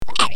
Slimy monster or murder sounds(9)
Squish
slimy_monster_or_murderd_sound_7.mp3